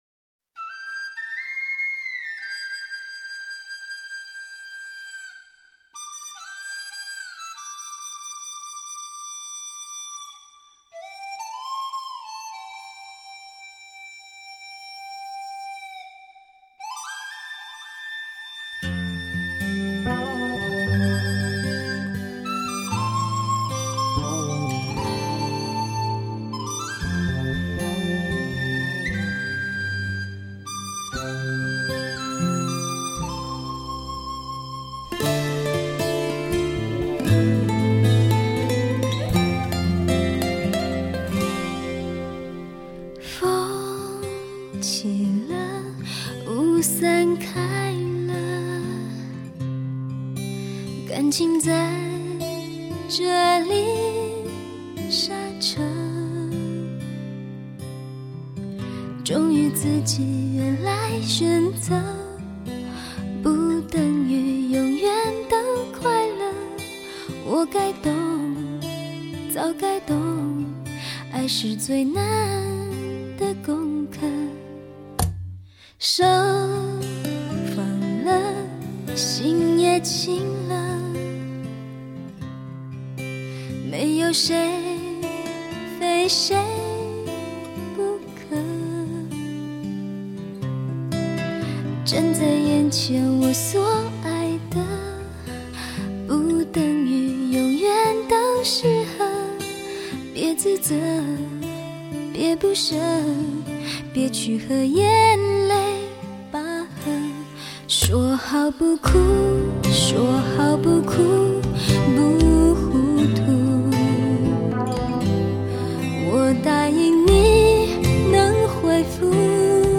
清澈见底的纯净声音
抚慰情感的纯酿美声